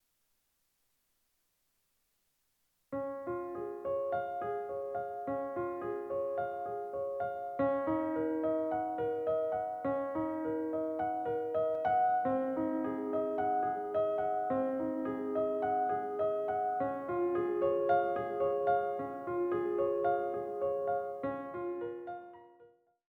Now listen to it again, this time instead of beginning in the scale or key of C, we're going to move every note up a half step, transposing it into the scale built on C#:
The first was harmonious, the second sounded completely out of tune.